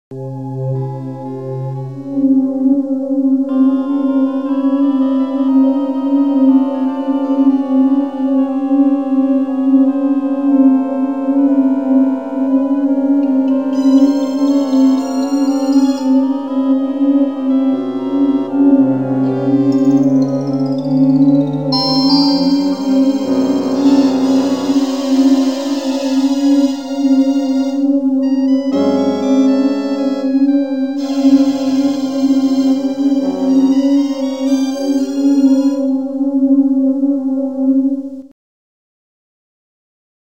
Daraus ergibt sich eine rhythmische Struktur eines 4/4 Taktes, die als horizontales Raster über die gesamten Fassaden gelegt wird.
Dies ergibt eine temperierte Rasterung von 4 Oktaven und zwei Halbtönen.